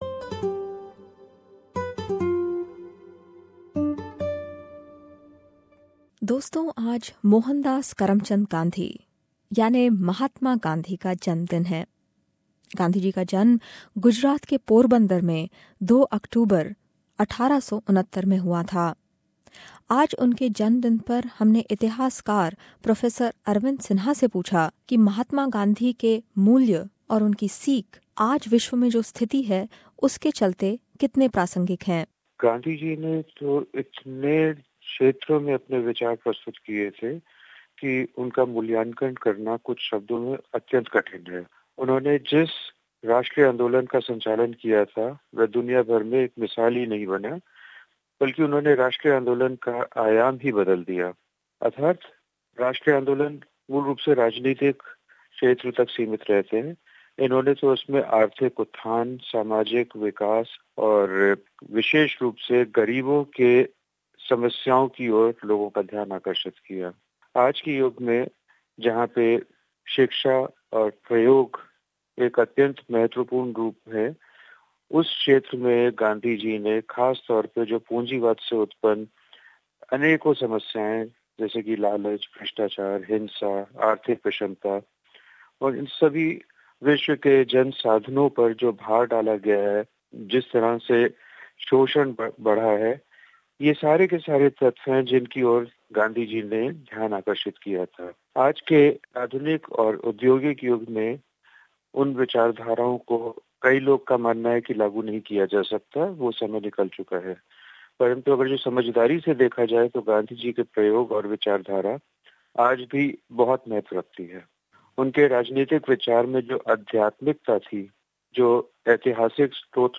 On the occassion of Gandhi Jayanti SBS Hindi presents this report as a tribute to the Father of the Nation, Mahatma Gandhi.